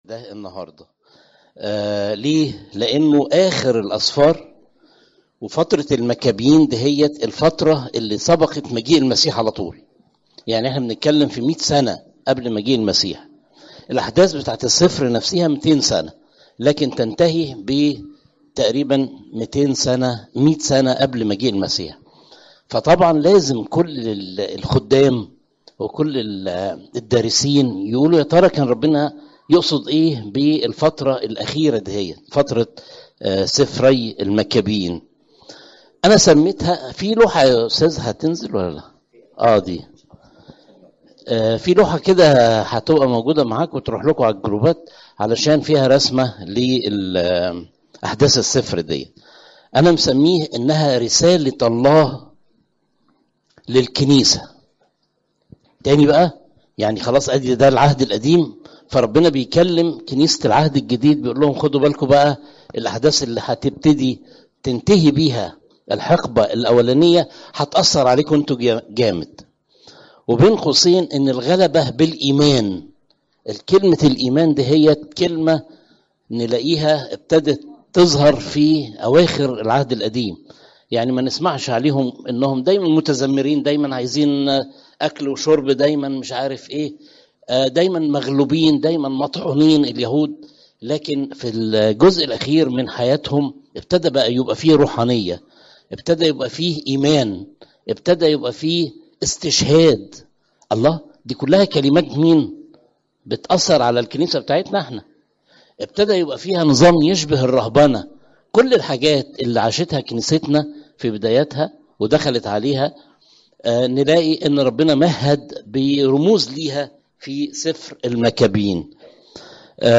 عظات الكنيسة - بطريركية الاقباط الارثوذكس - كنيسة السيدة العذراء مريم بالزيتون - الموقع الرسمي